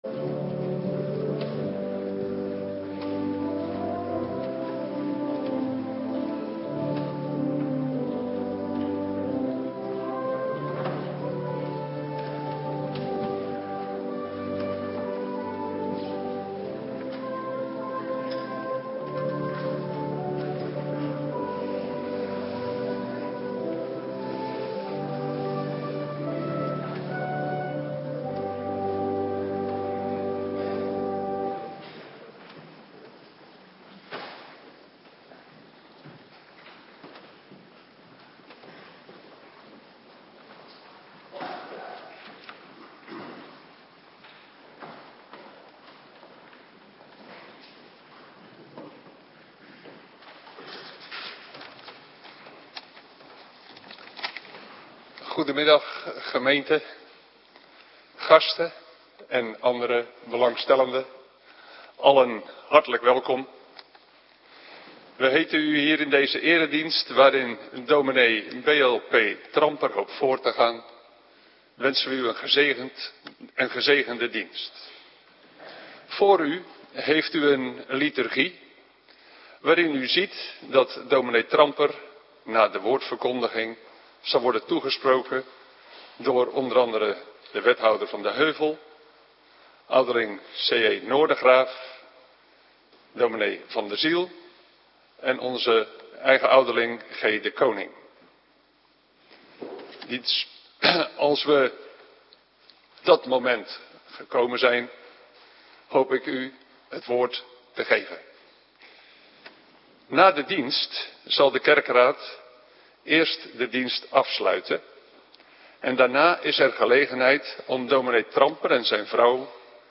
Intrededienst - Cluster B
Locatie: Hervormde Gemeente Waarder